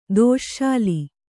♪ dōśśāli